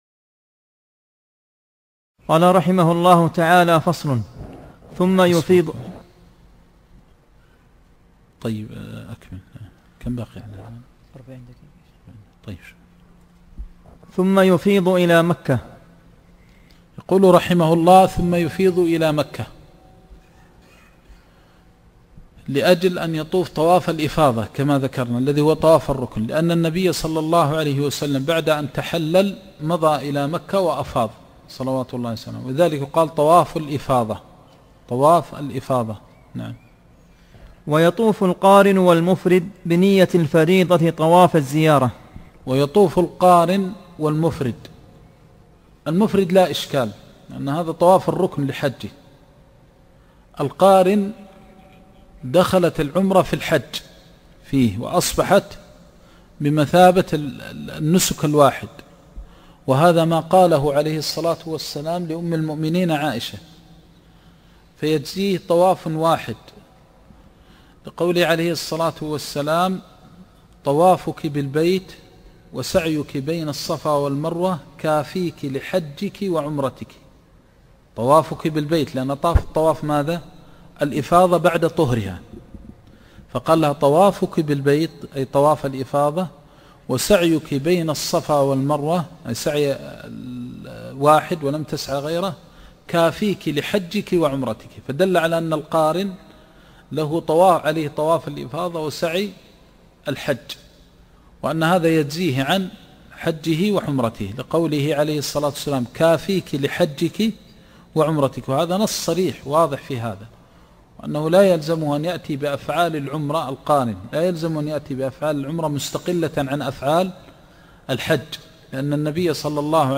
الفتاوى